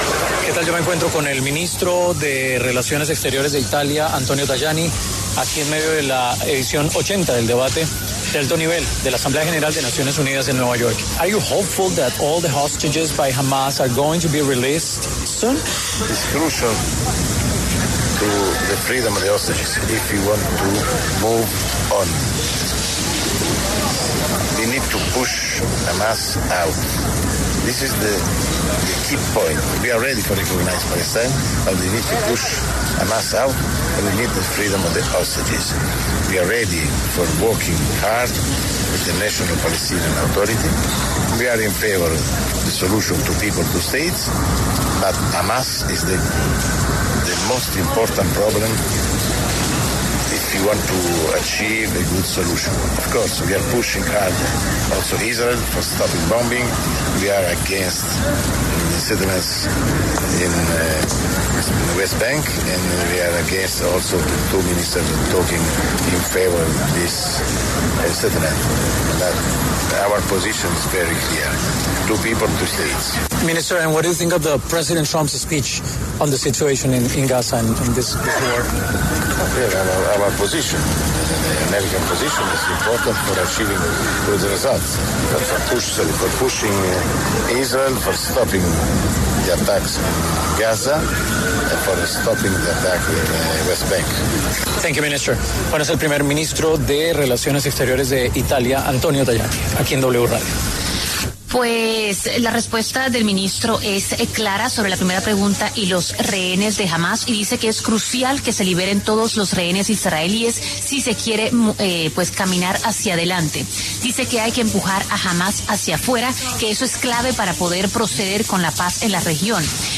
Antonio Tajani, vice primer ministro de Exteriores de Italia, en el marco de la Asamblea General ONU, pasó por los micrófonos de La W y se refirió a la guerra entre Palestina e Israel.